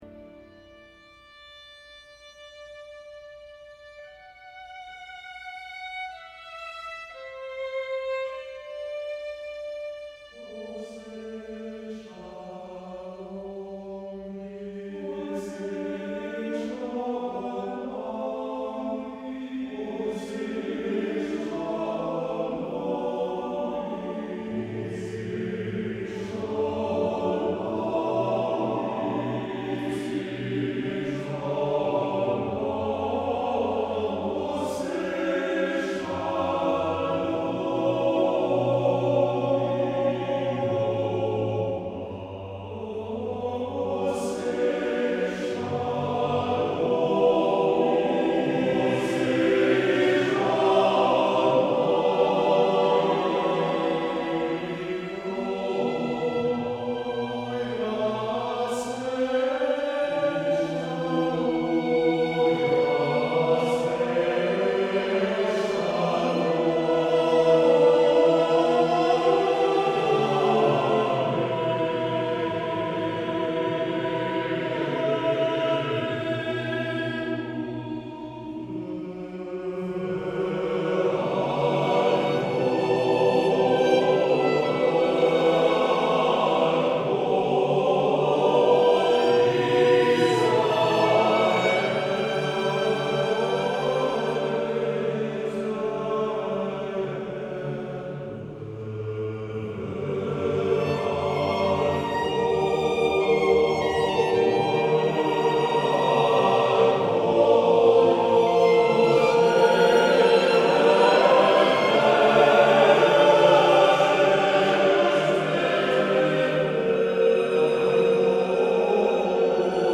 TTBB version